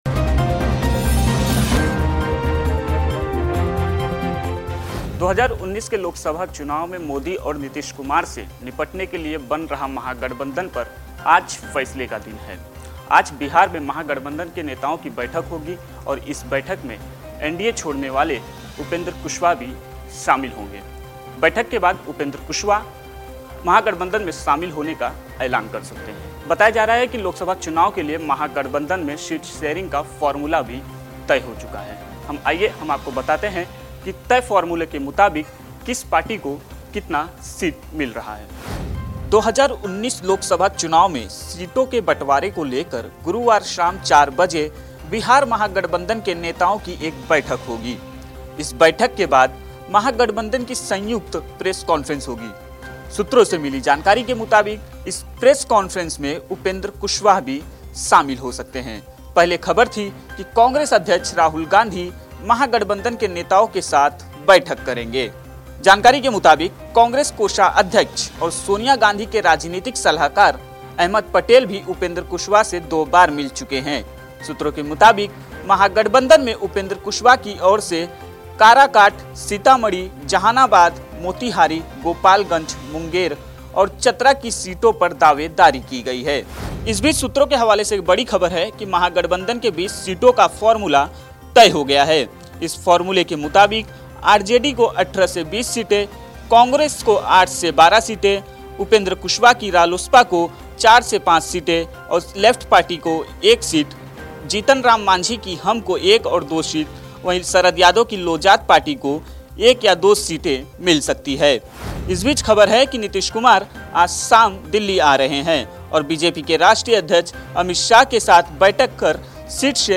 न्यूज़ रिपोर्ट - News Report Hindi / क्या कांग्रेस के होंगे उपेंद्र कुशवाह ?